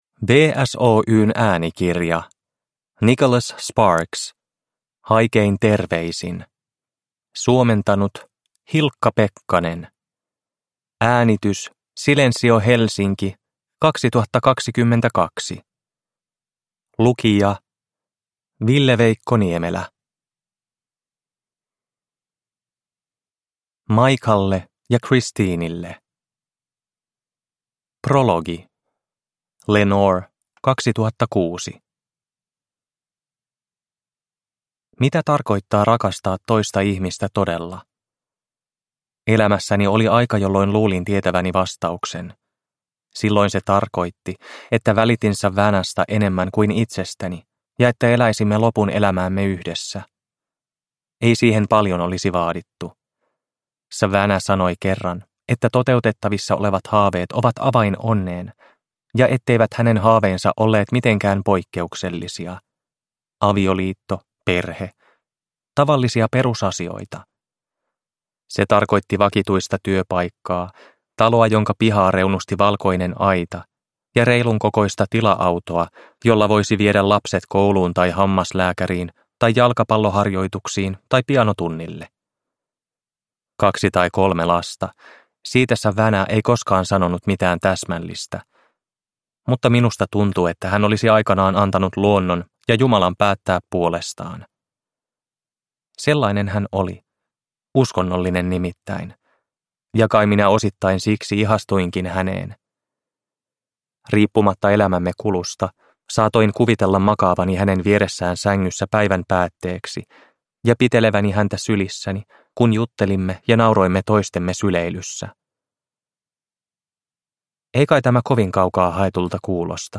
Haikein terveisin – Ljudbok – Laddas ner